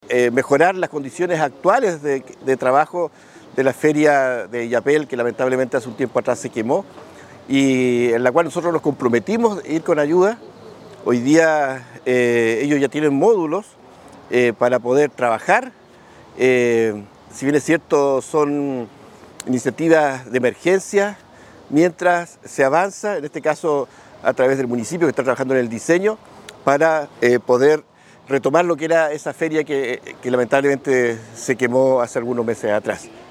PEDRO-VALENCIA-CORE.mp3